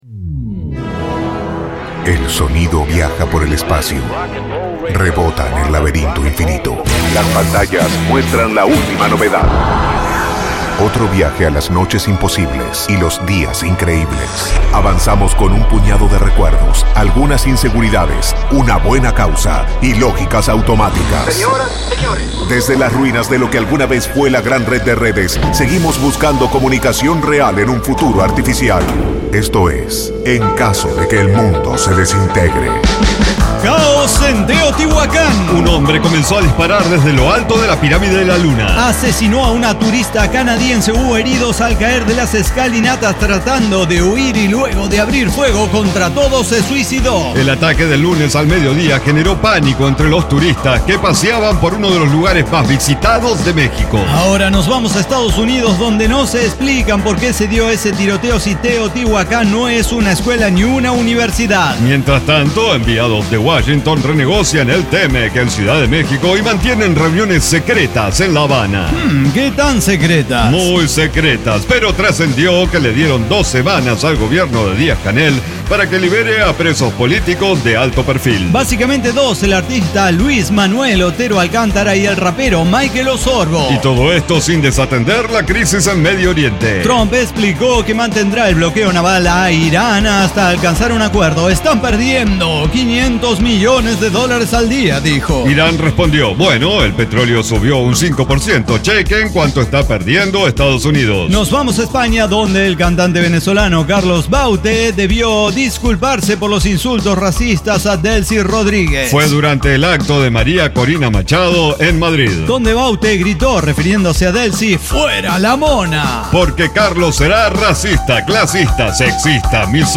NO AI: ECDQEMSD Podcast no utiliza ninguna inteligencia artificial de manera directa para su realización. Diseño, guionado, música, edición y voces son de nuestra completa intervención humana.